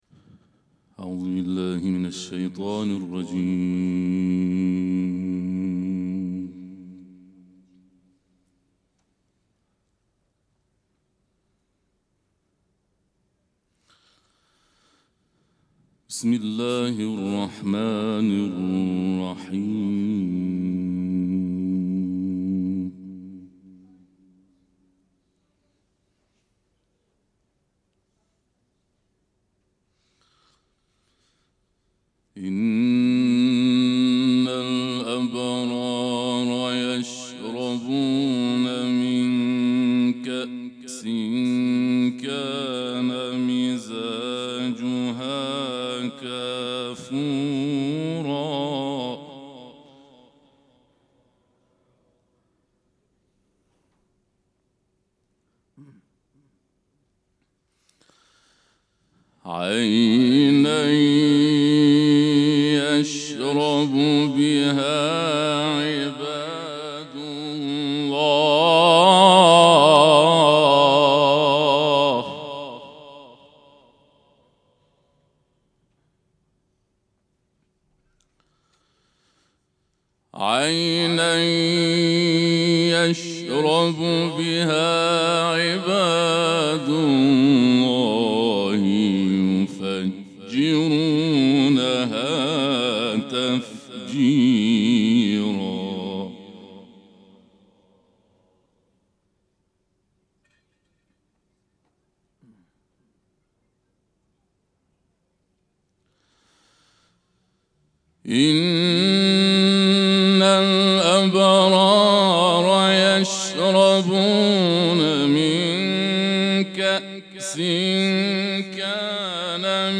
قرائت قرآن